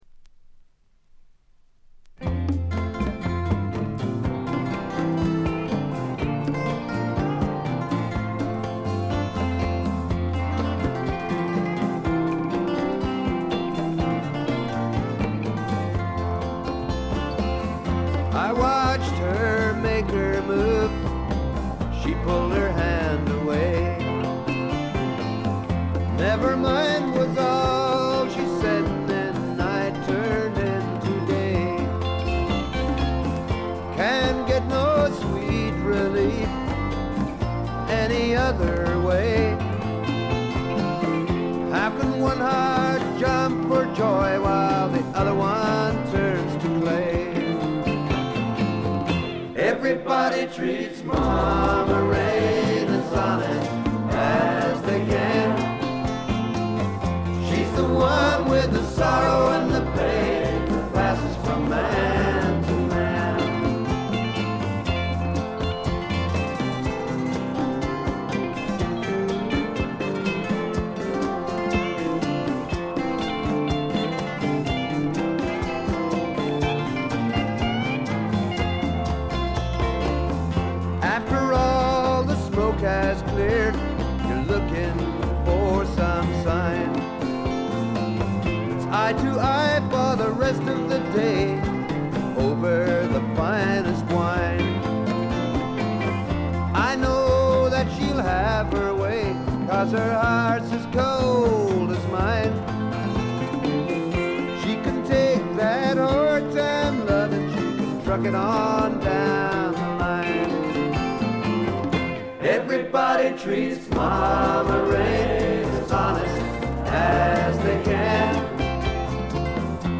わずかなノイズ感のみ。
録音もベルギーで行われており、バックのミュージシャンも現地組のようです。
試聴曲は現品からの取り込み音源です。
Vocals, Backing Vocals, Guitar, Harmonica